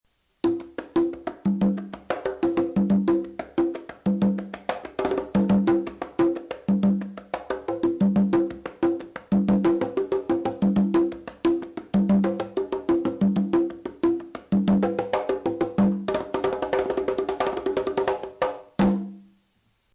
A large drum developed in Cuba from an African predecessor. They come in many sizes to give different tones, and create a percussive melody. The three main sizes are the tumba, the lowest pitch; the conga, middle pitch; the quinto, the highest pitch.
conga.mp3